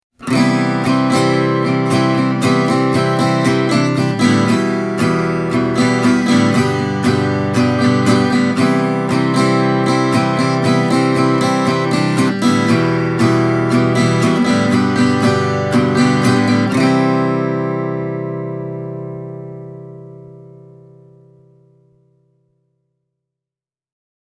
Parlour-kitara sopii erinomaisesti sormisoittoon, leveän kaulansa ansiosta. Soundi on hyvin balanssissa, vaikka se voi kuulostaa nykykorville ehkä vähän keskiäänivoittoiseksi.
Tältä Parlour-kokoinen kitara kuulostaa:
sormisoitto